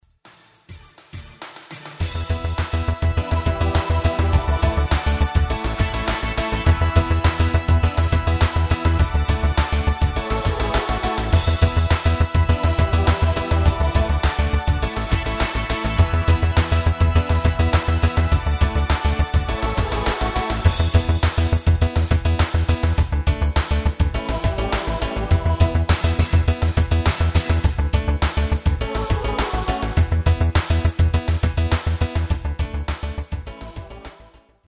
Demo/Kauf Midifile
Stil: Pop & Rock International
Tonart: C